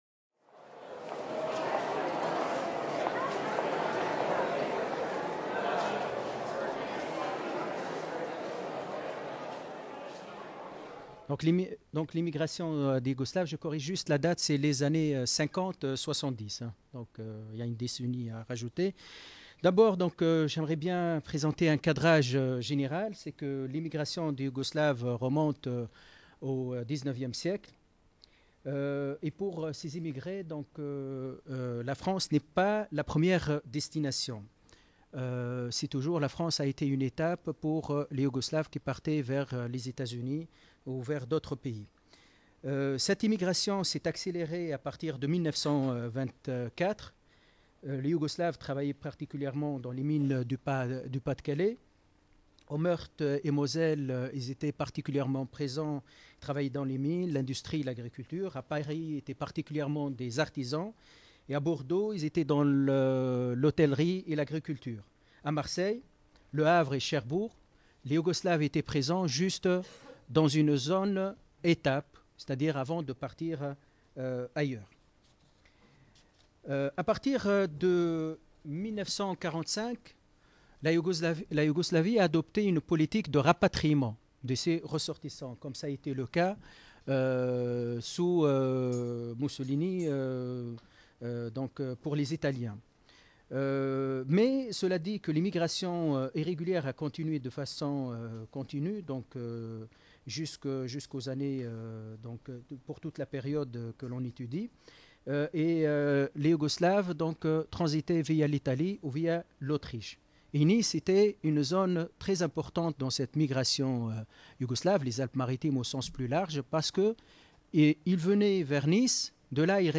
Journée d’étude organisée par l’Urmis et l’Observatoire des Migrations dans les Alpes-Maritimes à la MSHS de Nice, le jeudi 6 décembre 2018.